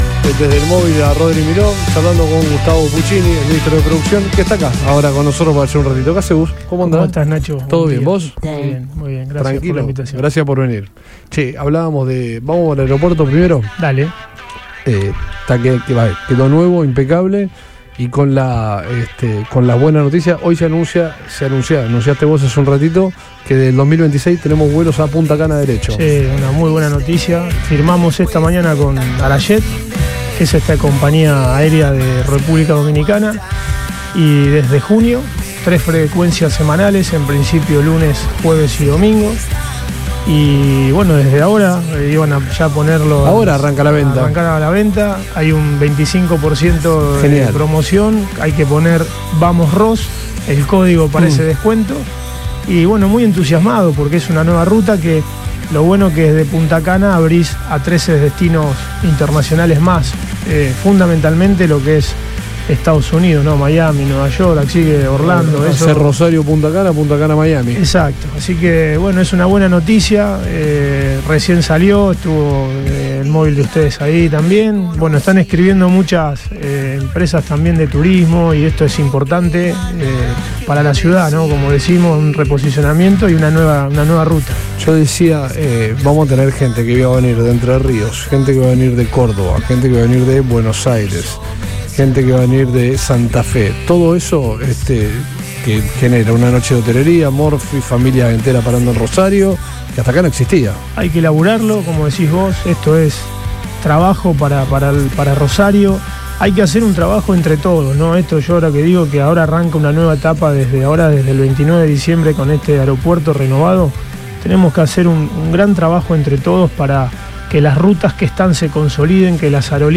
El ministro de Desarrollo Productivo, Gustavo Puccini, visitó los estudios de Radio Boing y dejó títulos fuertes sobre el futuro de la región. En diálogo con el programa Todo Pasa, el funcionario confirmó que la terminal de Fisherton entrará en una nueva era desde el 29 de diciembre con infraestructura de nivel mundial y un aumento masivo de vuelos.